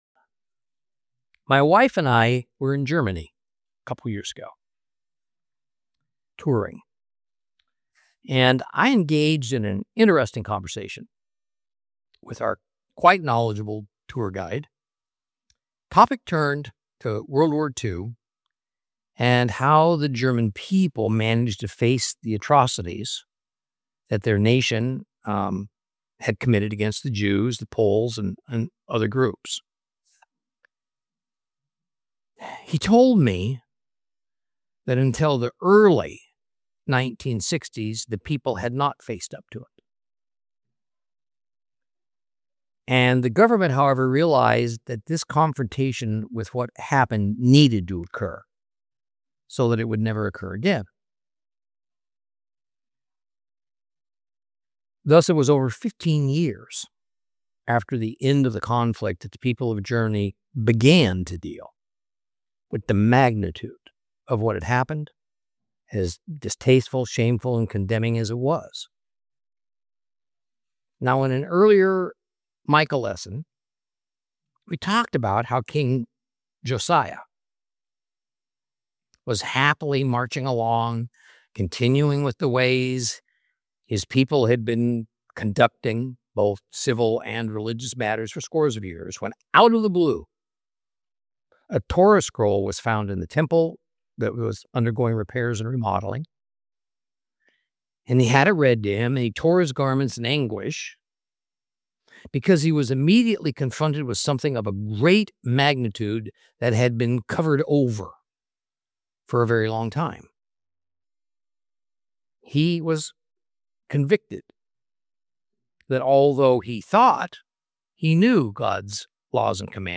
Teaching from the book of Micah, Lesson 13 Chapter 7.